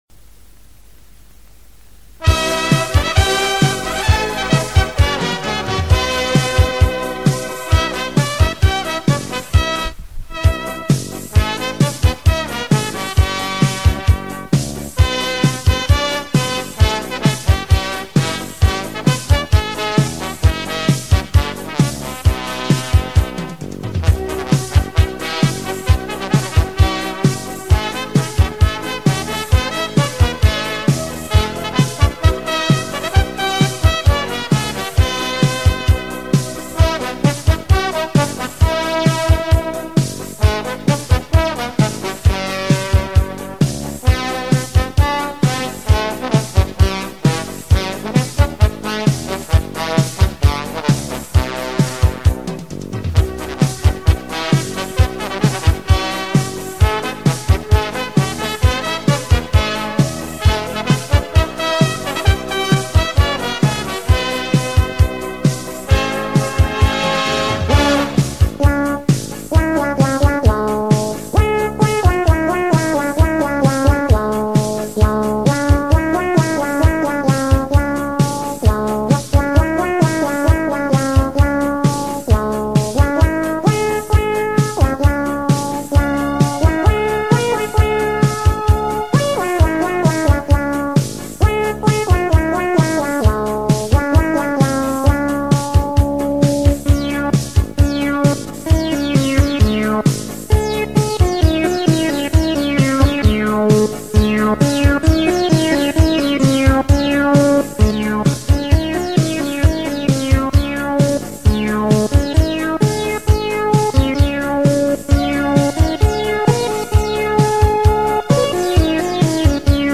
怀旧，经典